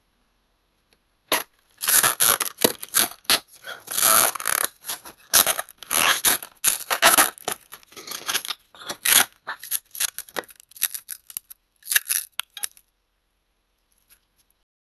Free AI Sound Effect Generator
Мальчика бьет отец а мальчик кричит и стонет